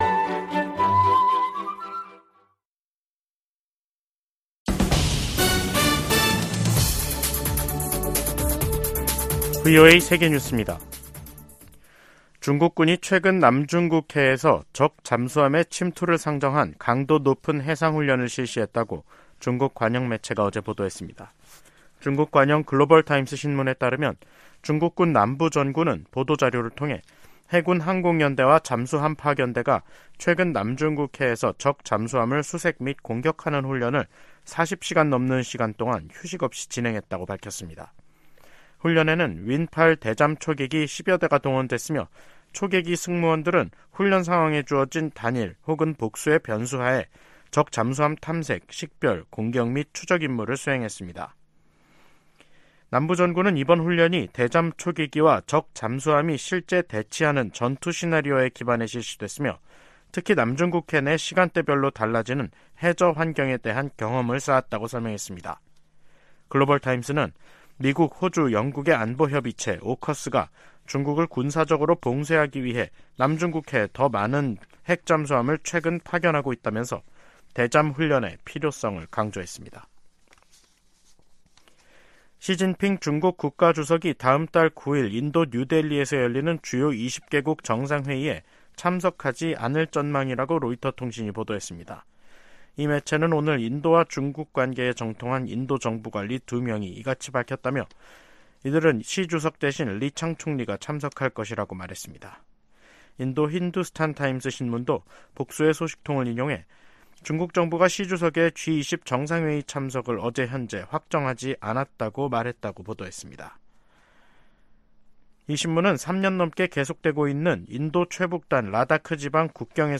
VOA 한국어 간판 뉴스 프로그램 '뉴스 투데이', 2023년 8월 31일 2부 방송입니다. 북한이 미한 연합훈련에 반발해 동해상으로 탄도미사일을 발사 했습니다.